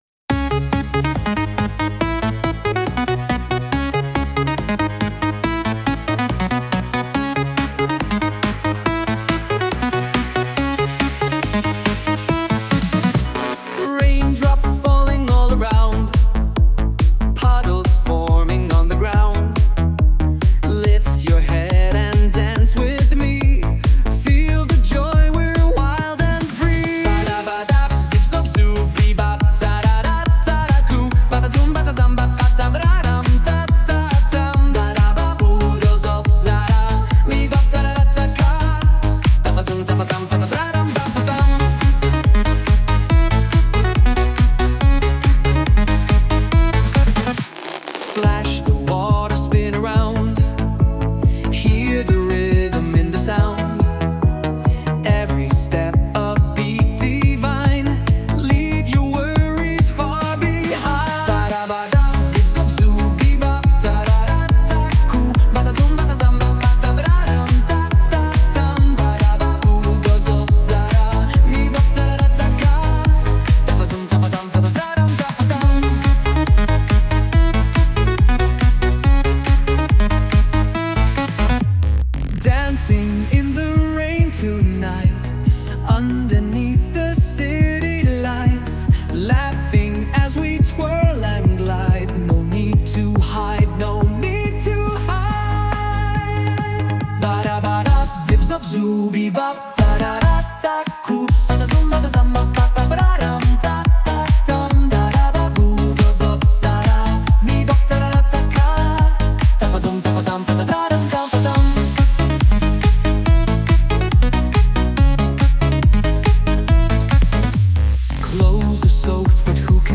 Ale testowałem jak będzie brzmiała moja nietypowa i napisana przeze mnie 'z palca' wokaliza i zafascynował mnie styl disco lat 80tych z głębokimi basami.